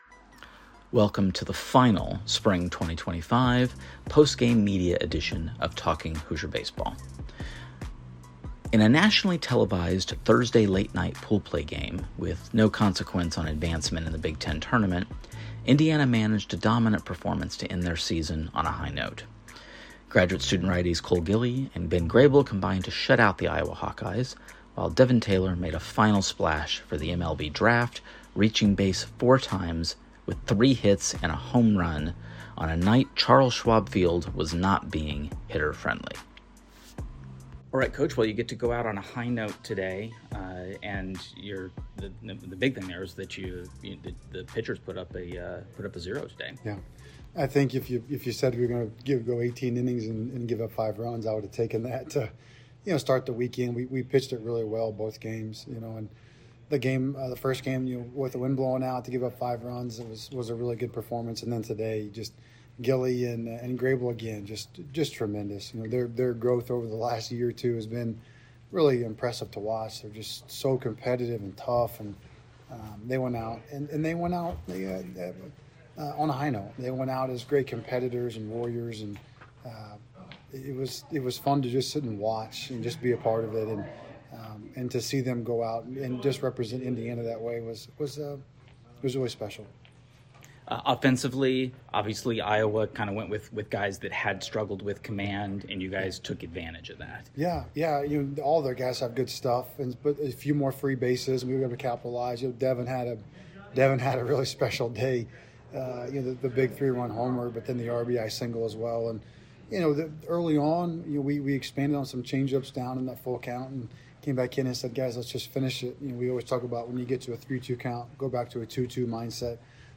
Postgame Media B1G Tournament Pool Play vs. Iowa